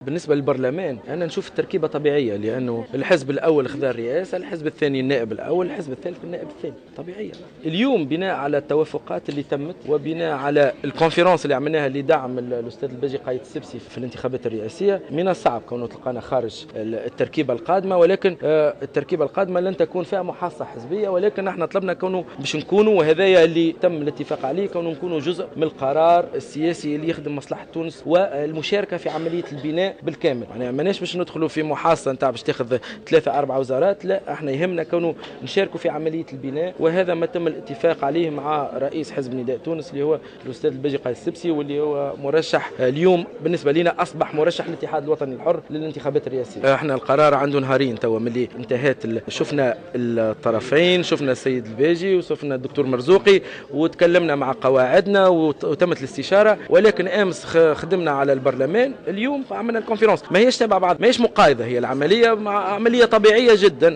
أعلن رئيس الاتحاد الوطني الحر سليم الرياحي اليوم الجمعة 5 ديسمبر 2014 خلال ندوة صحفية عن دعمه لمرشح حركة نداء تونس للدور الثاني من الانتخابات الرئاسية الباجي قائد السبسي مؤكدا أن الباجي قايد السبسي أصبح اليوم مرشح الإتحاد الوطني الحر للرئاسية.